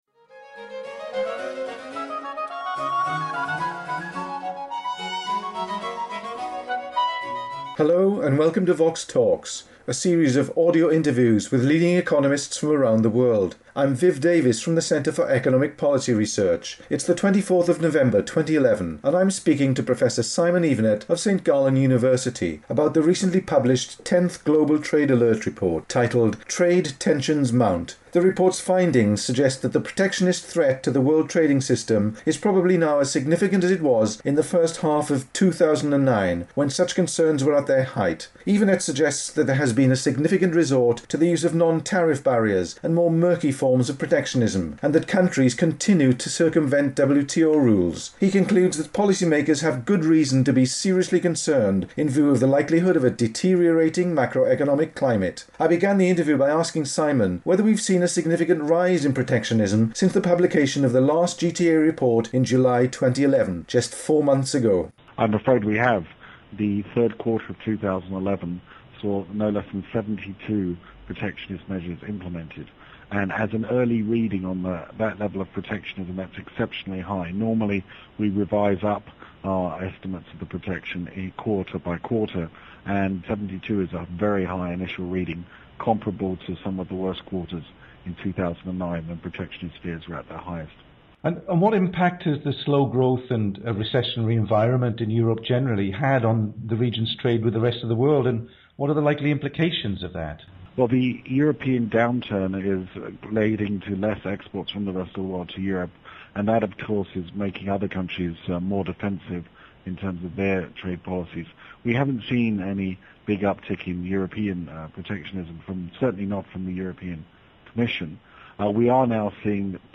The interview was recorded on 24 November 2011.